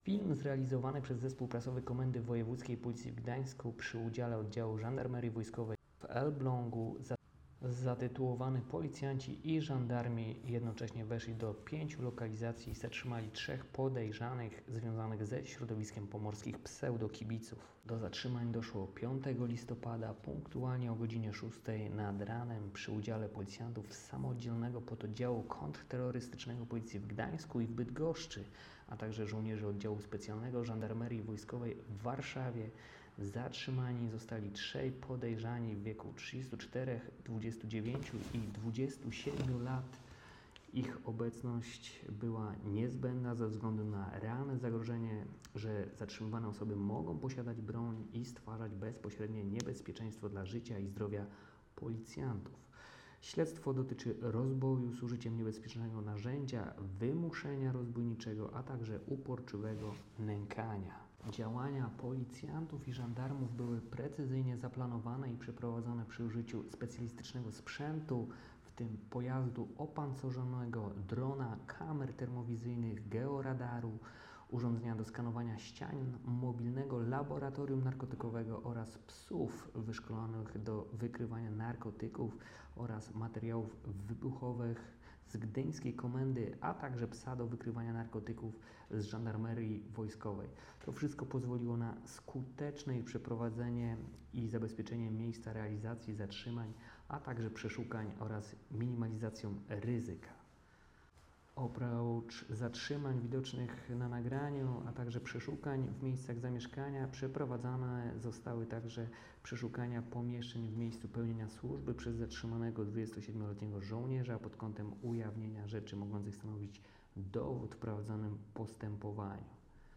Nagranie audio audiodyskrypcja__4_.m4a